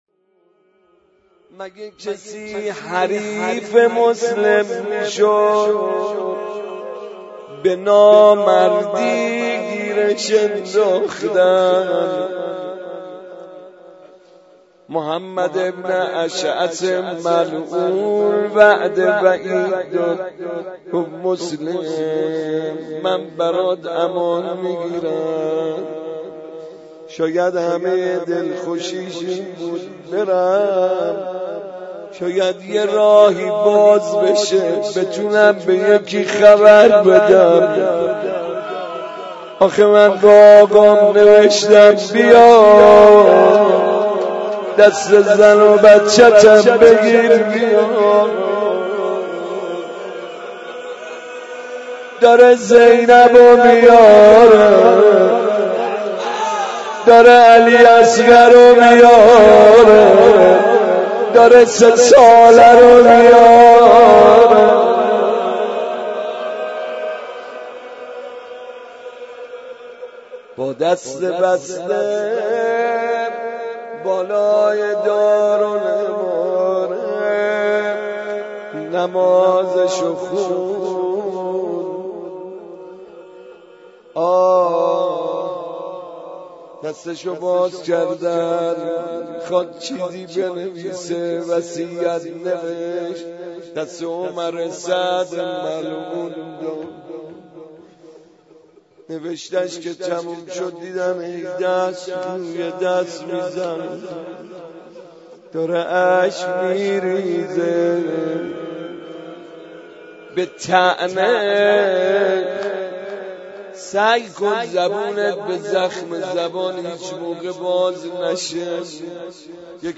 مراسم شب بیستم و ششم ماه مبارک رمضان
مداحی
مناجات
روضه
شور